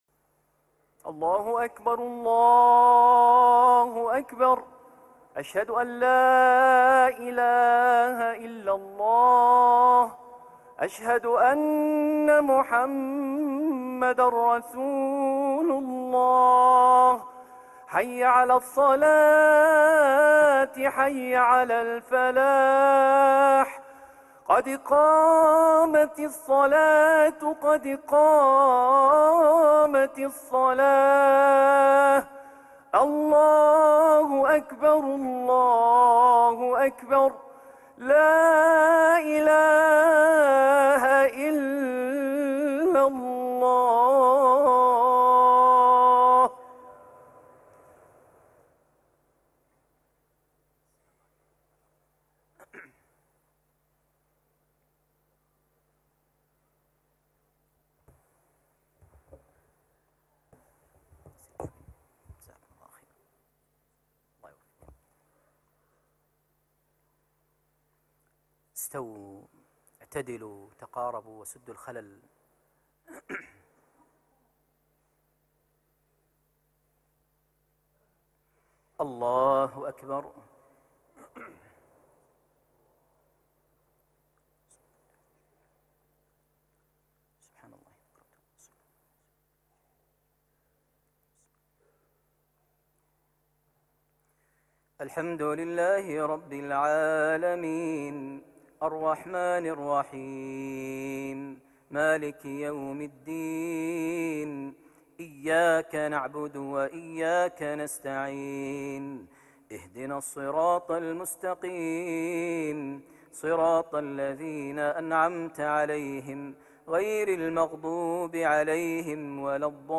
من الكويت | صلاة الجمعة ١٣ جماد الأول ١٤٣٥هـ لسورتي الأعلى - الغاشية > زيارة الشيخ ماهر المعيقلي لدولة الكويت ١٤٣٥هـ > المزيد - تلاوات ماهر المعيقلي